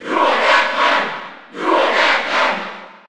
Category:Duck Hunt (SSB4) Category:Crowd cheers (SSB4) You cannot overwrite this file.
Duck_Hunt_Cheer_Spanish_PAL_SSB4.ogg